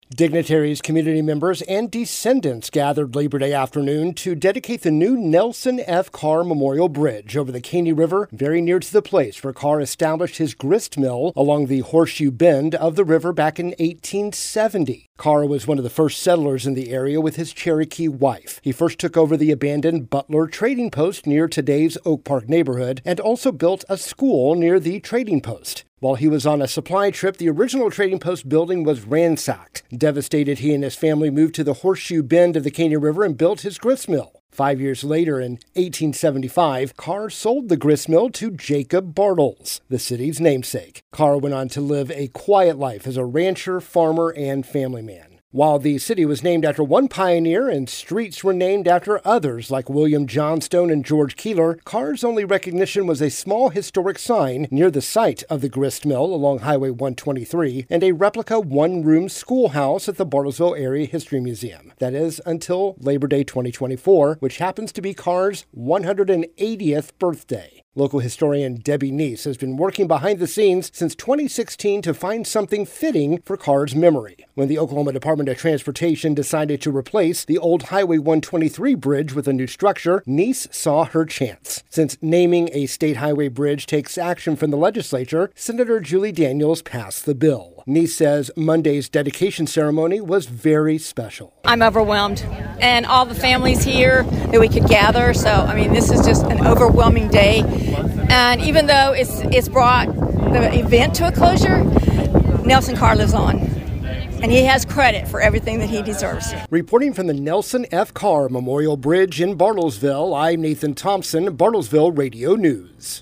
Nelson F Carr Bridge Dedication 9-2.mp3